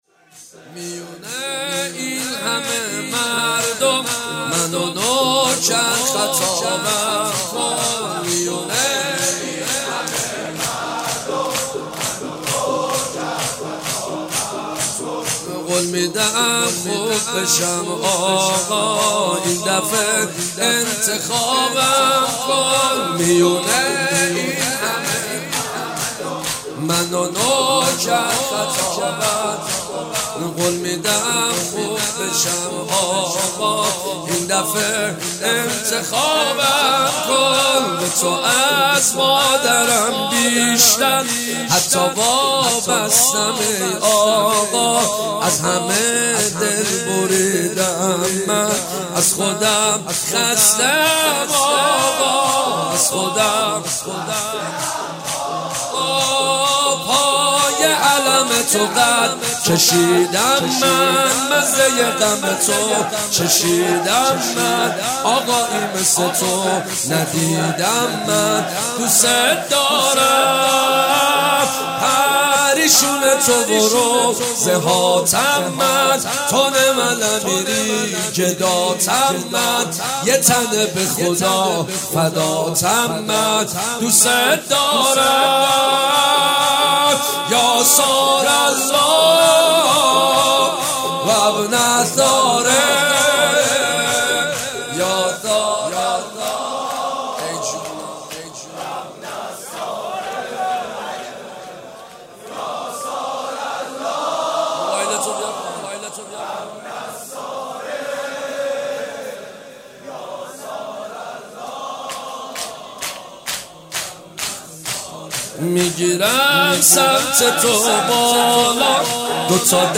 مداحی محرم